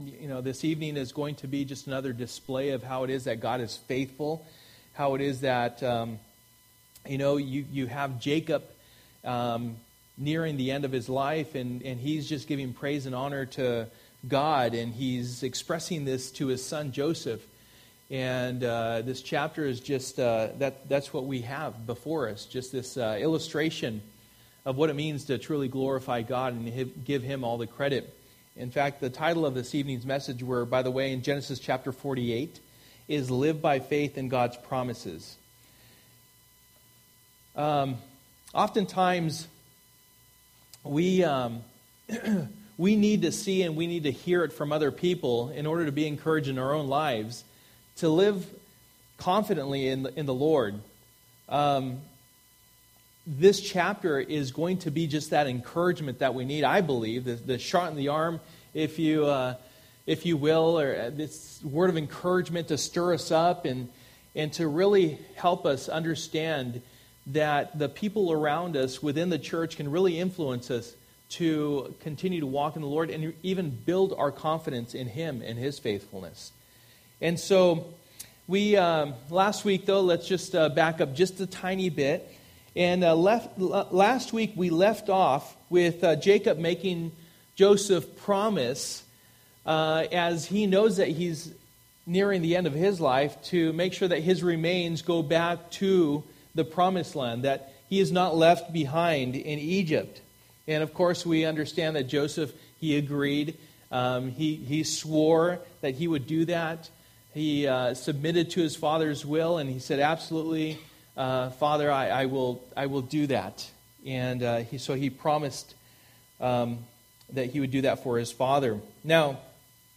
Through the Bible Passage: Genesis 48:1-22 Service: Wednesday Night %todo_render% « Show Her the Door